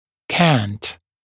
[kænt][кэнт]наклон, скос, наклонная плоскость; наклонный, косой; наклонять